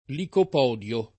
vai all'elenco alfabetico delle voci ingrandisci il carattere 100% rimpicciolisci il carattere stampa invia tramite posta elettronica codividi su Facebook licopodio [ likop 0 d L o ] s. m. (bot.); pl. ‑di (raro, alla lat., ‑dii )